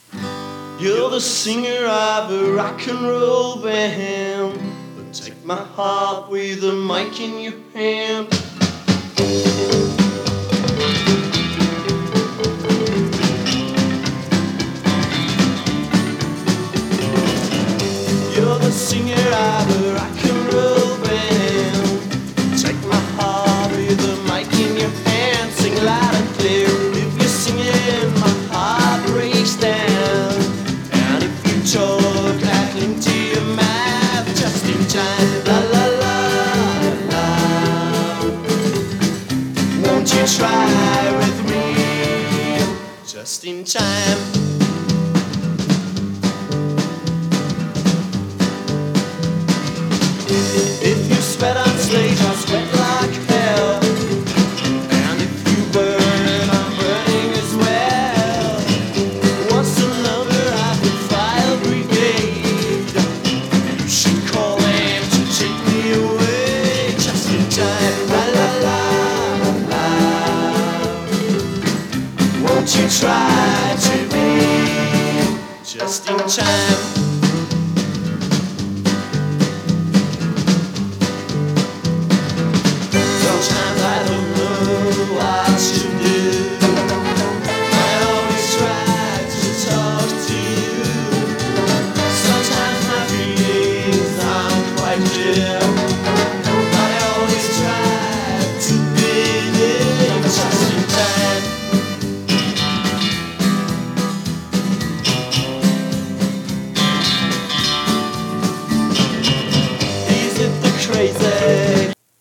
60年代のガレージ/サイケを昇華したサウンドと個性的な唄声でニューウェイヴィーな雰囲気も漂わせる作品です！